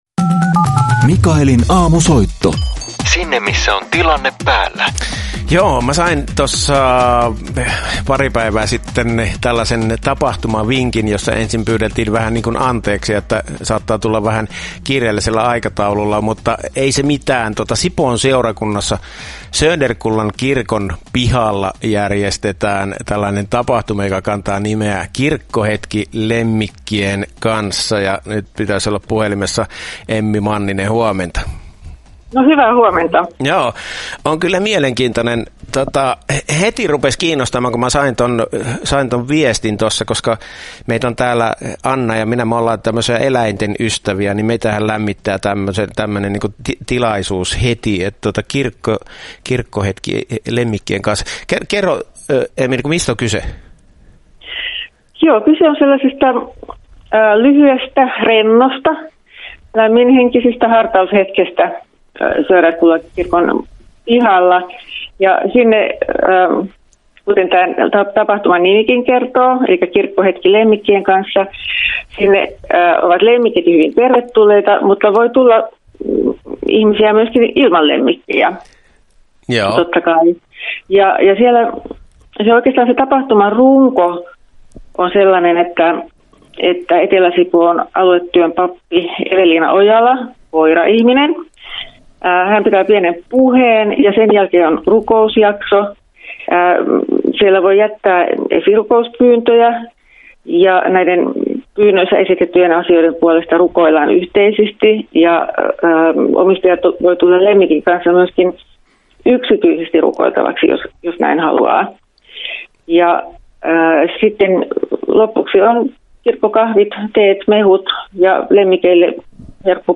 Herätys! -aamulähetyksessä kertomassa elämästä pandemian keskellä ja sen jälkeen